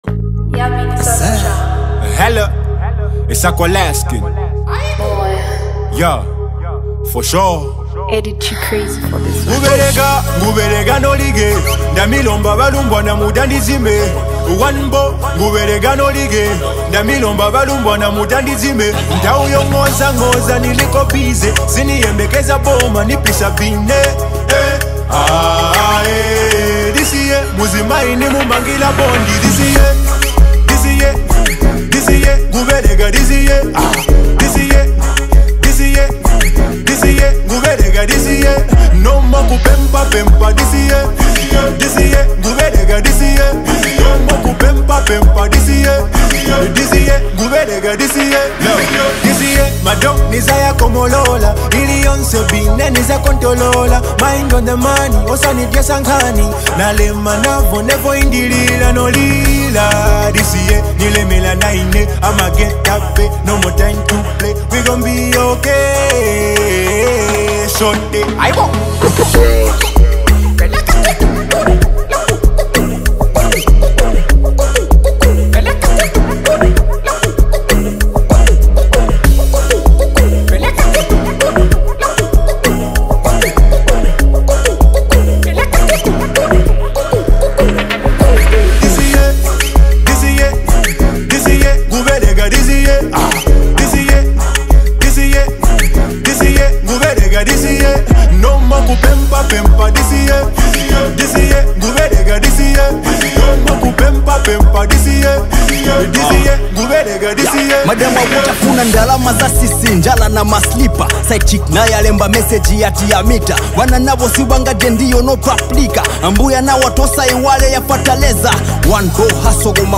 Zambian Music
smooth vocals
catchy hooks create an unforgettable sound